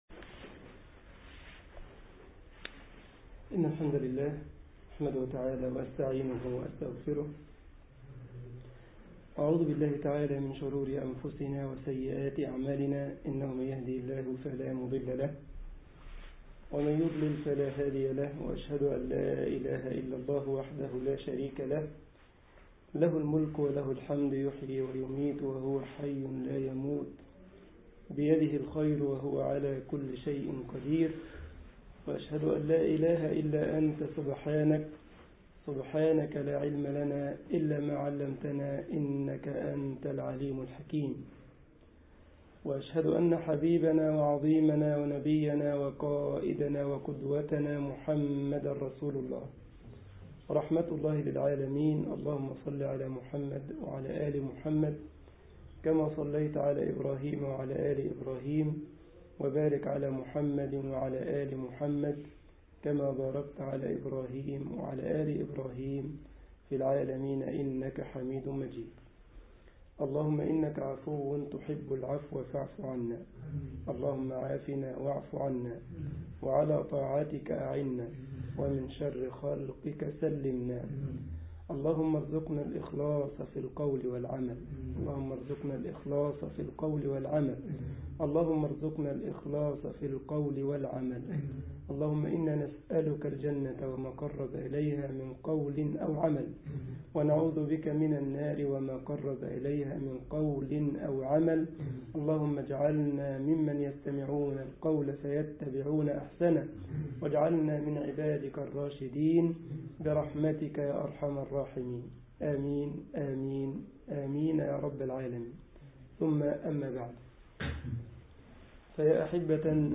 مسجد هومبرج التابع للجمعية الإسلامية بالسالند ـ ألمانيا محاضرة